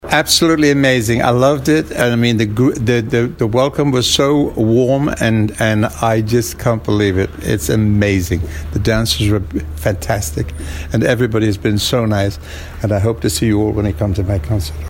Le célèbre crooner britannique, Engelbert Humperdinck, est arrivé jeudi soir à l’aéroport de Tahiti-Faa’a. Le chanteur de 82 ans a esquissé quelques pas de danse polynésienne devant ses fans venus le couronner, avant de le retrouver dimanche soir sur la scène de To’ata.